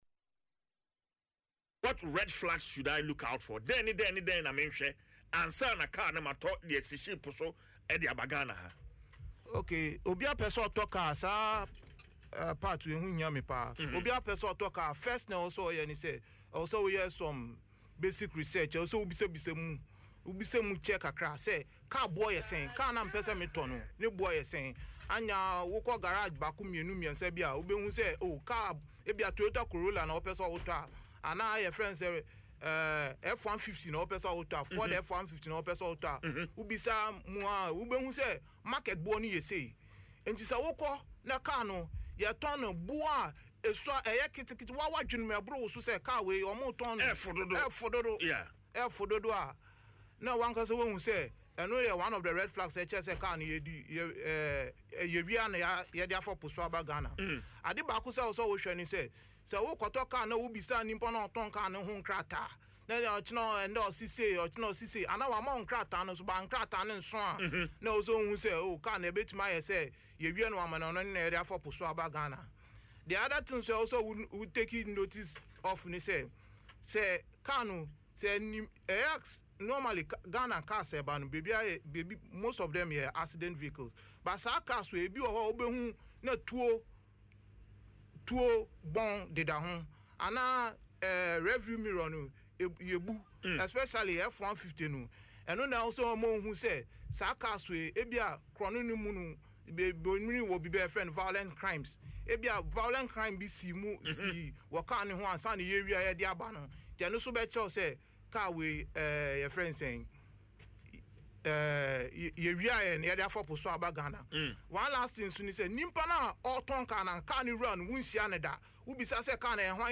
Speaking on Adom FM’s Dwaso Nsem